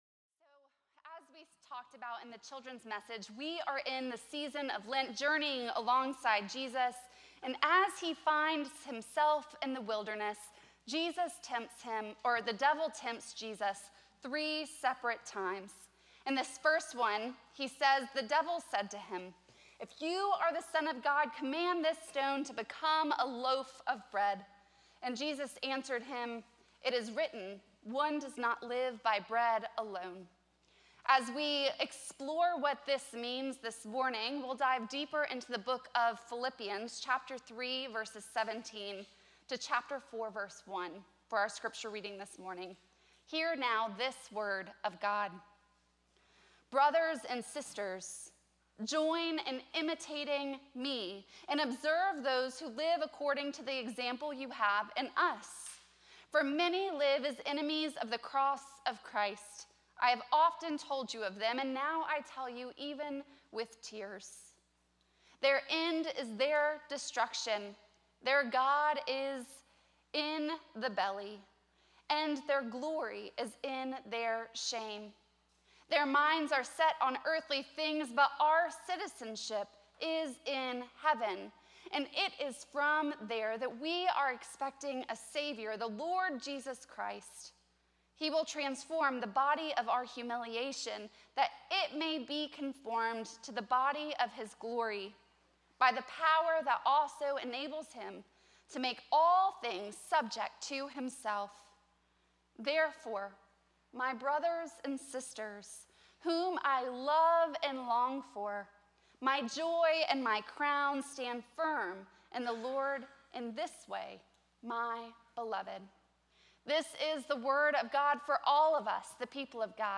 First Cary UMC's First Sanctuary Sermon